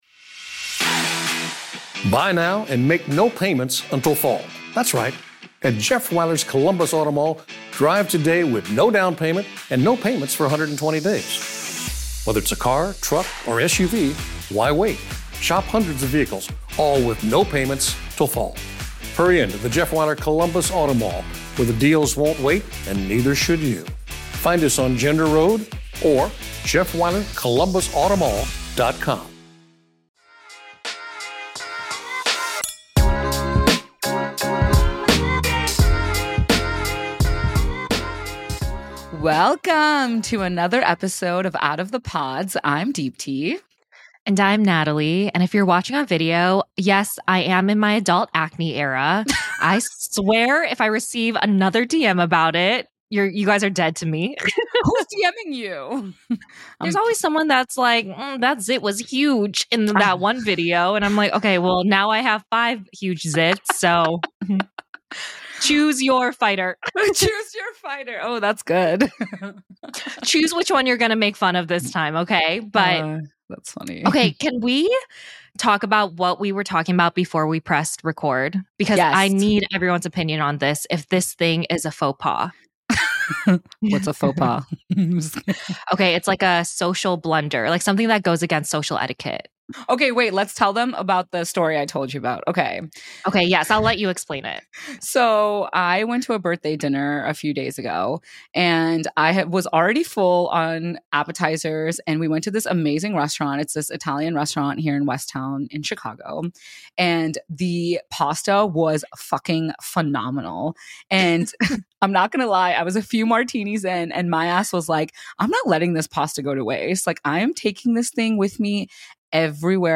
34. The Burning Questions Series: Love is Blind Q&A